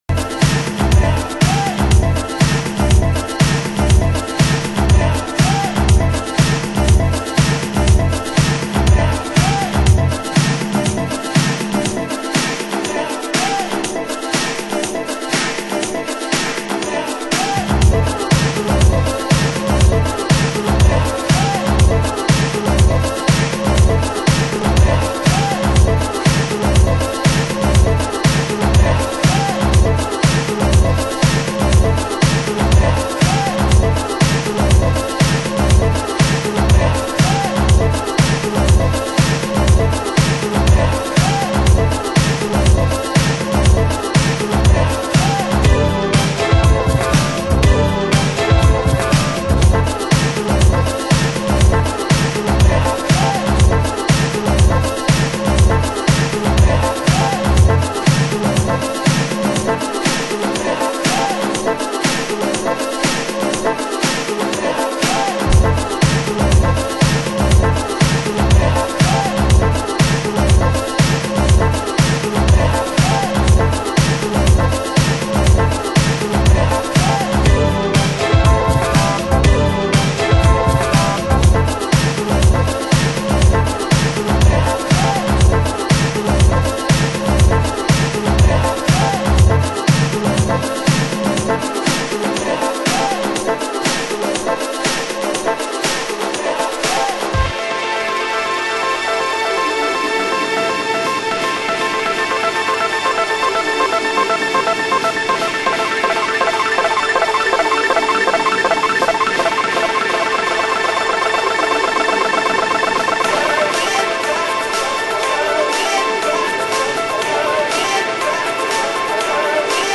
★DISOCDUB NUHOUSE